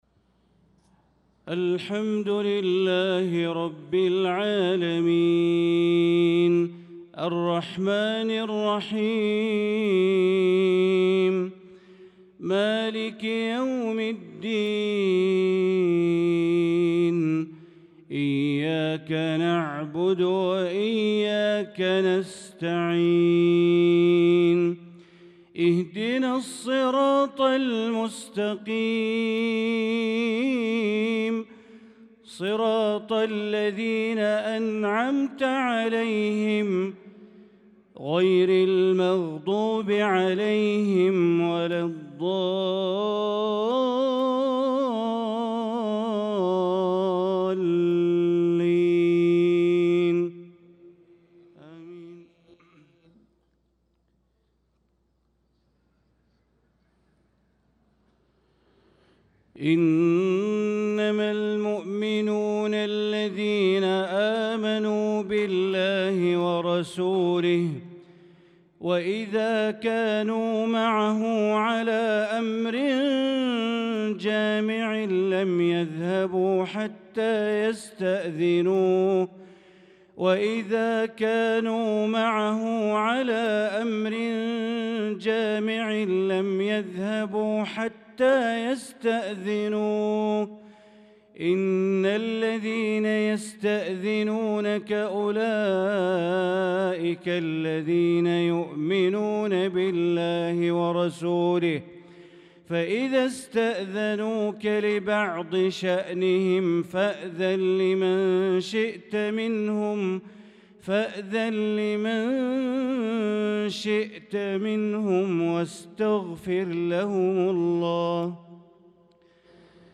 صلاة العشاء للقارئ بندر بليلة 16 شوال 1445 هـ